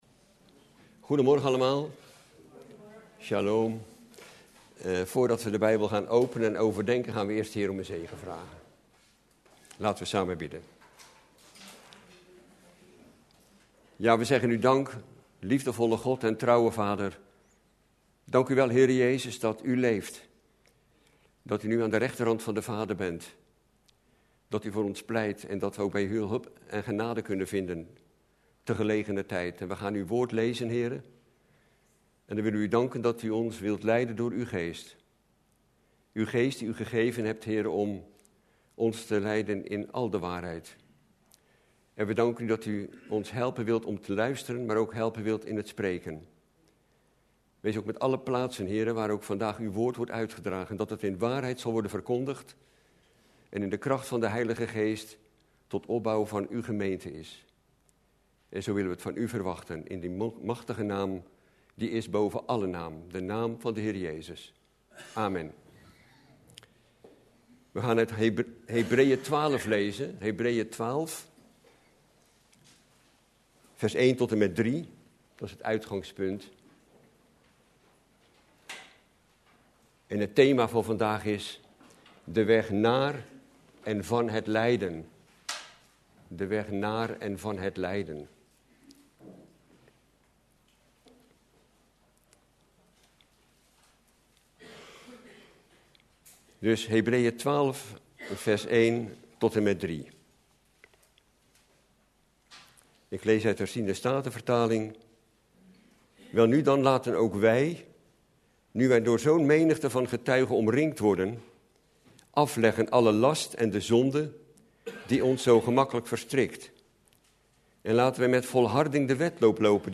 In de preek aangehaalde bijbelteksten